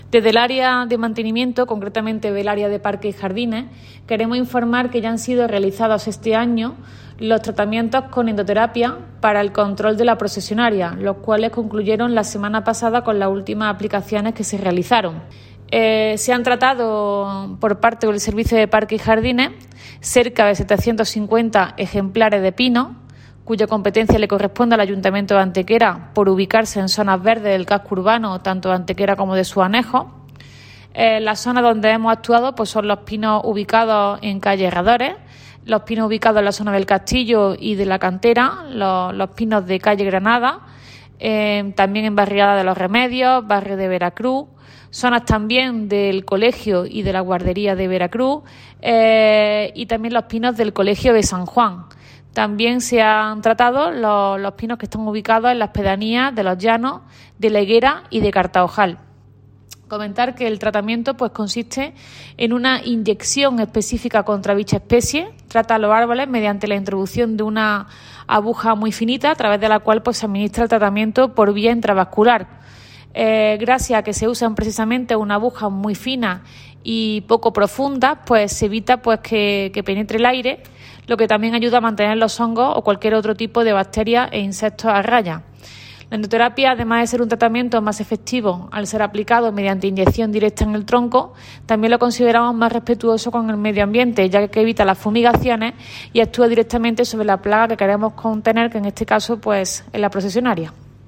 Cortes de voz T. Molina 790.31 kb Formato: mp3